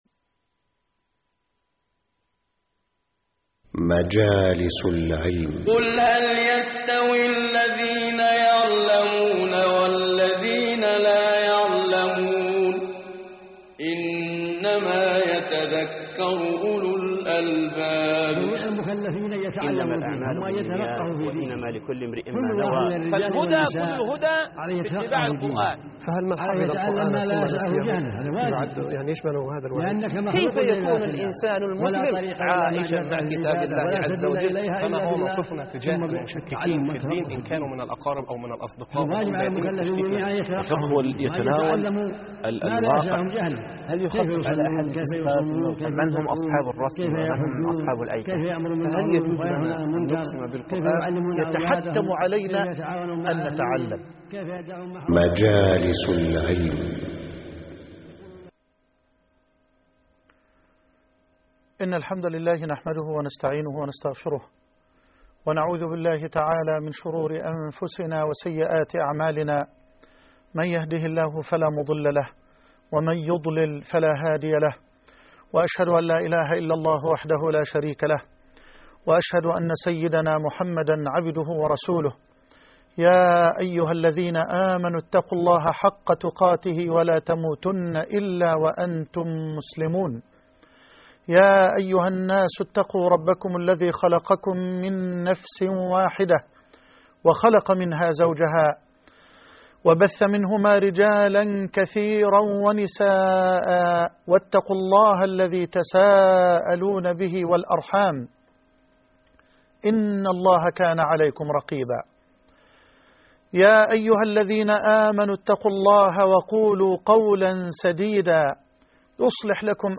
المجلس (30) الدرس الأخير